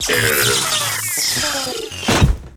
droid.ogg